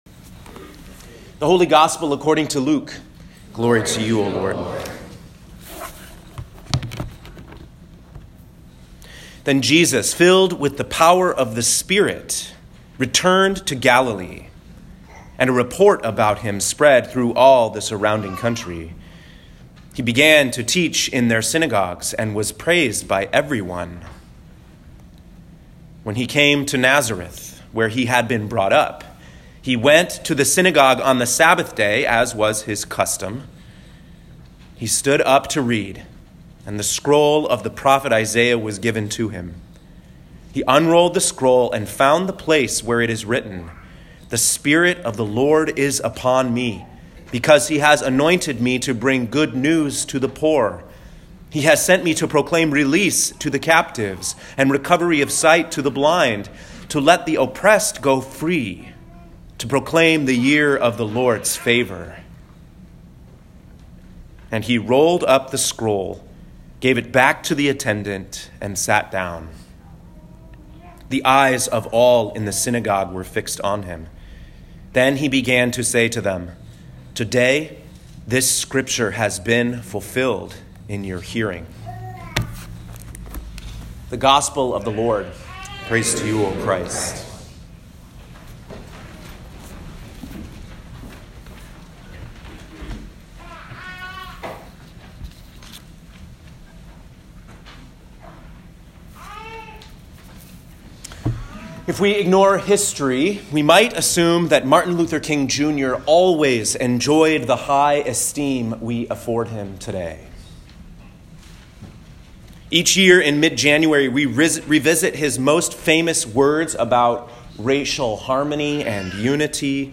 Home › Sermons › Jesus’ Mission Statement